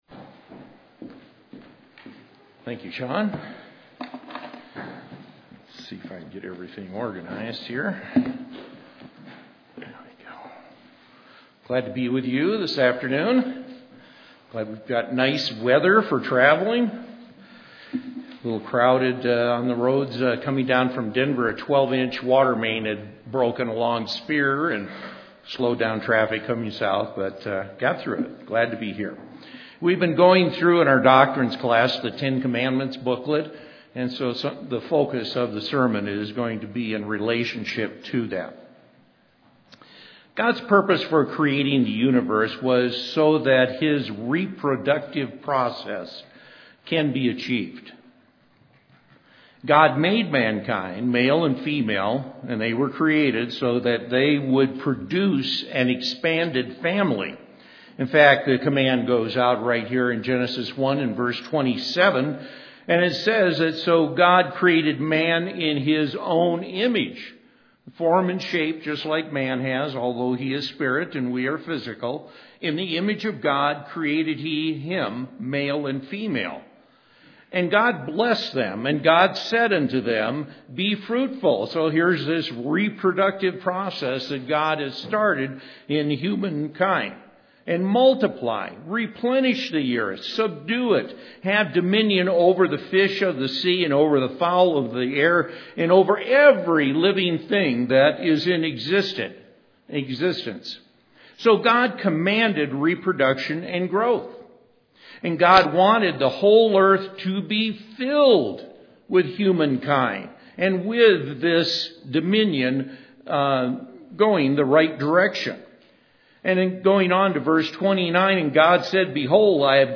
Given in Colorado Springs, CO